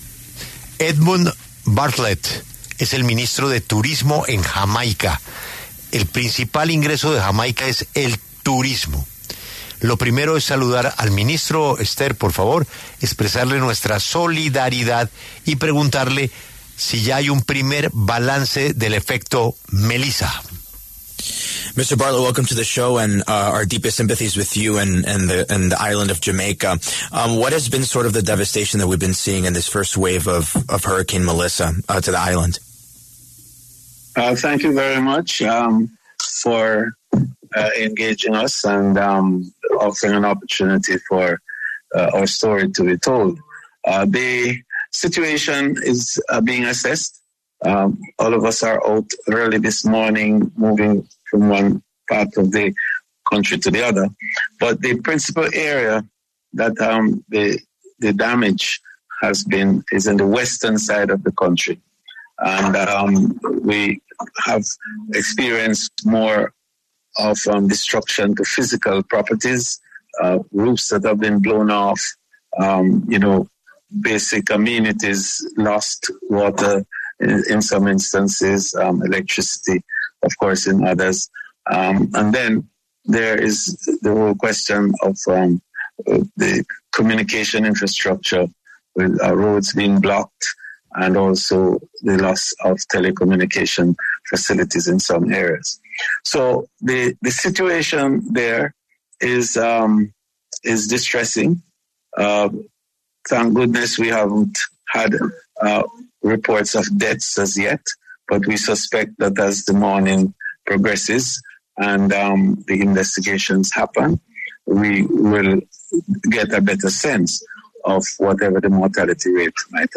Edmund Bartlett, ministro de Turismo de Jamaica, conversó con La W sobre las afectaciones al país y a los turistas tras la emergencia por el huracán Melissa.